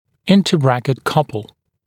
[ˌɪntə’brækɪt ‘kʌpl][ˌинтэ’брэкит ‘капл]межбрекетная пара (сил)